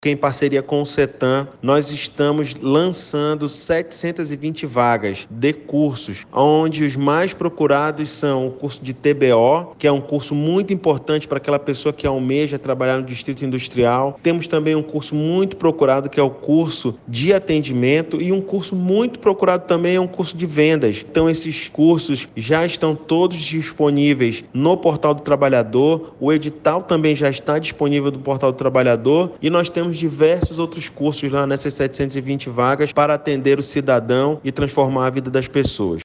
Os cursos oferecidos são voltados para indivíduos que buscam melhorar suas habilidades e aumentar suas chances de inserção profissional, como explica o secretário executivo do Trabalho e Empreendedorismo, Paulo Gilson.
SONORA-1-PAULO-GILSON-.mp3